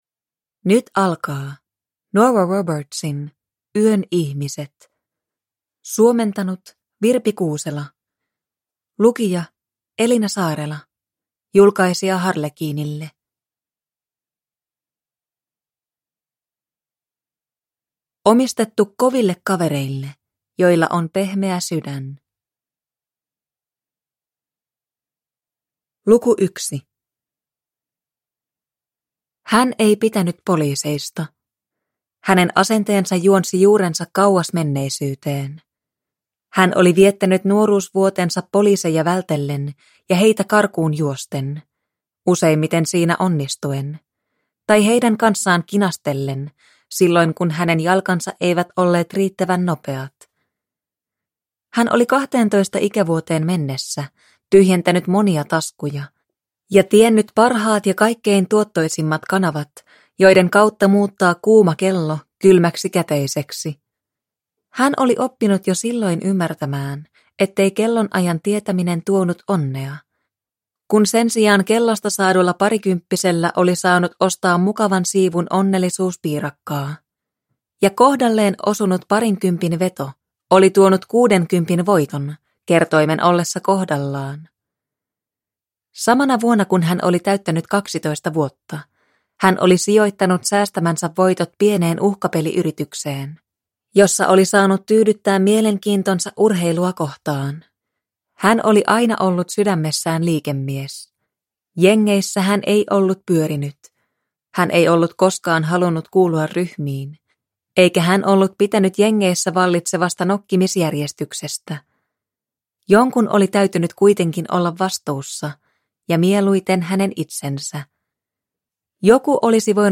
Yön ihmiset – Ljudbok